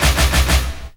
02_30_drumbreak.wav